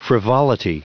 Prononciation du mot frivolity en anglais (fichier audio)
frivolity.wav